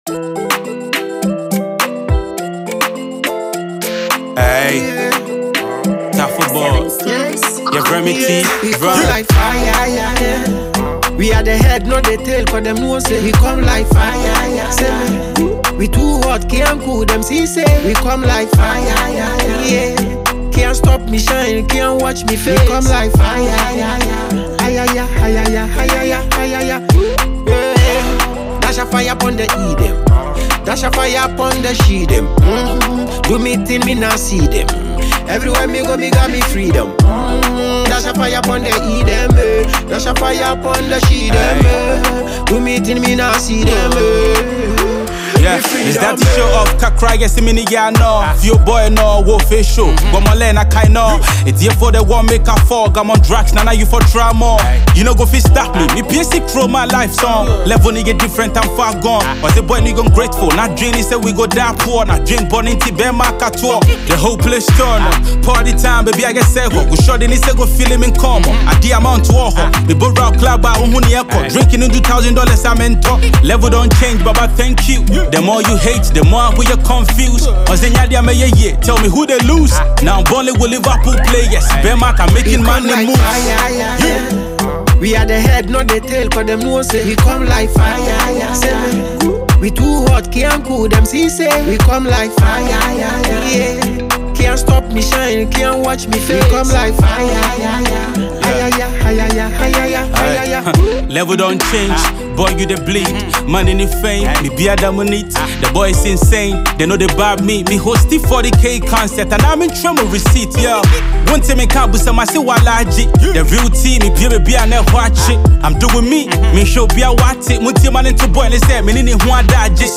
Stylish gang rapper
poum music